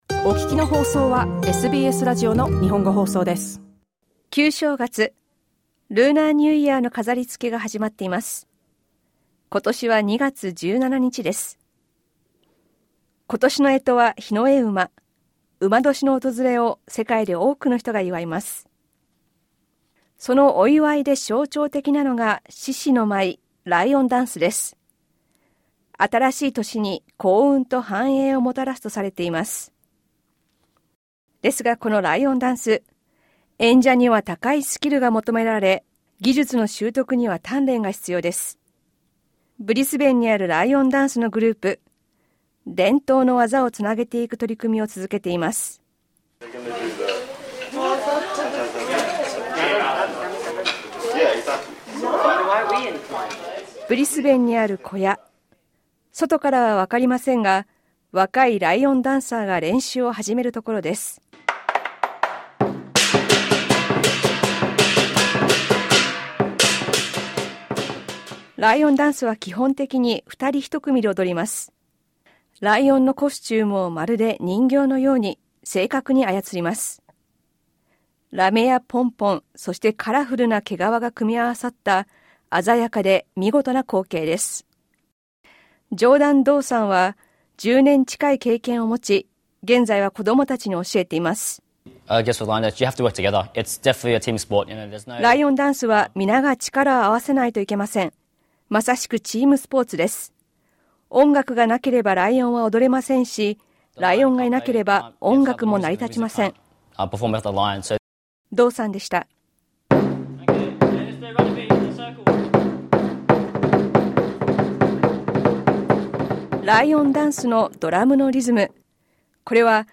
旧正月を前に、ブリスベンにあるグループに話を聞いたリポートです。